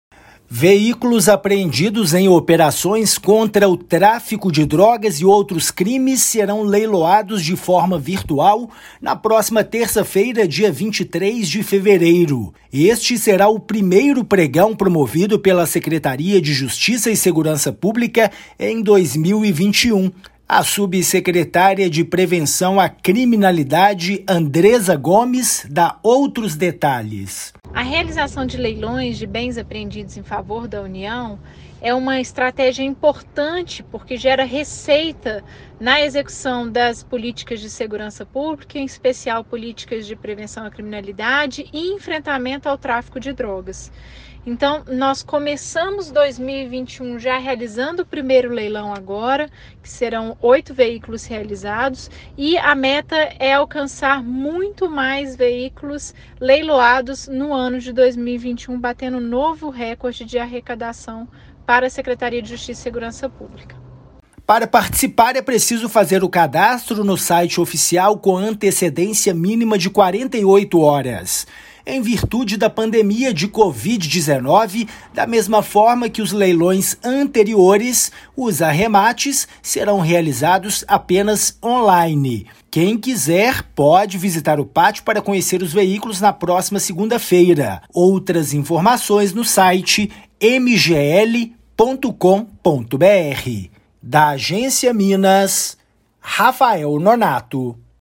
Veículos estão disponíveis para o arremate; ação busca angariar recursos e reforçar políticas de prevenção à criminalidade no estado. Ouça matéria de rádio.
MATÉRIA_RÁDIO_LEILÃO_VEICULOS.mp3